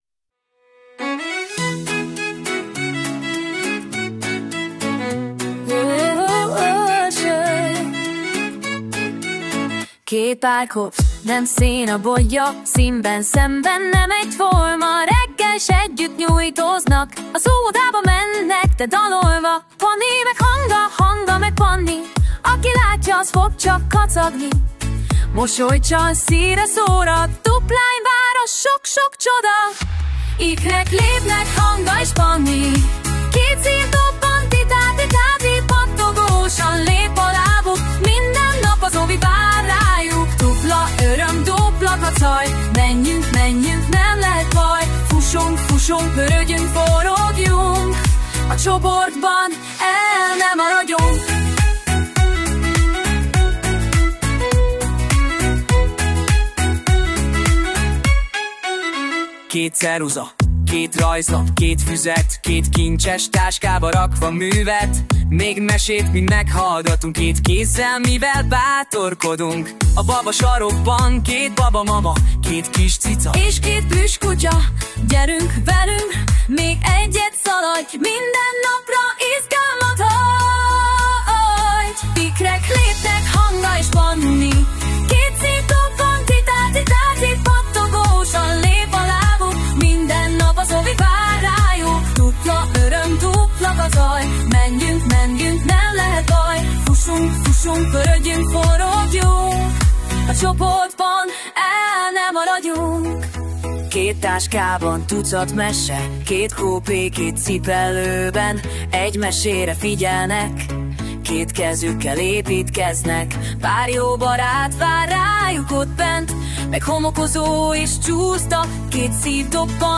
VersZenés vers